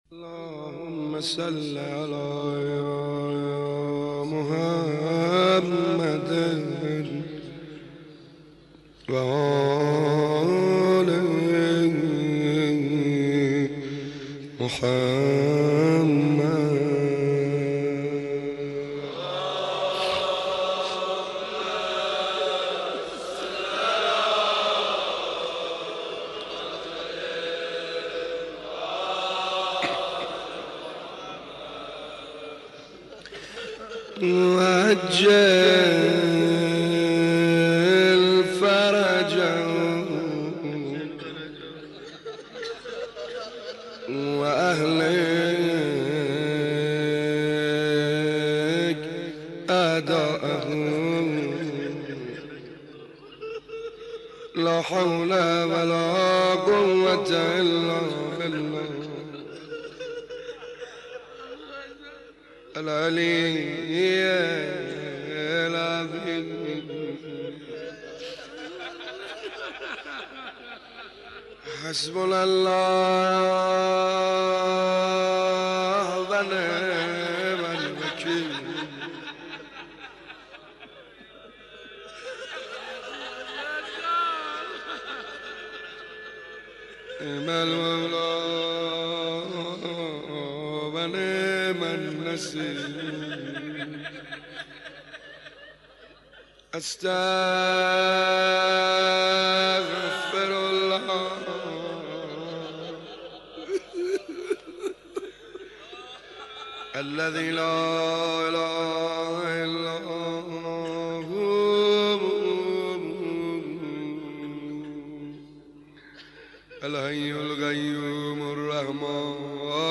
مناسبت : شب سوم محرم